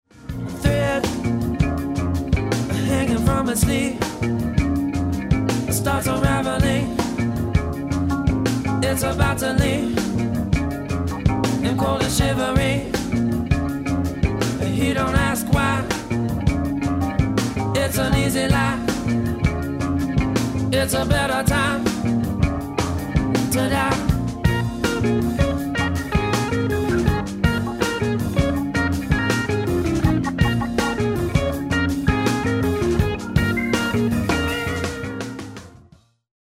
This is a great studio in theTexas Hill Country.